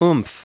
Prononciation du mot oomph en anglais (fichier audio)
Prononciation du mot : oomph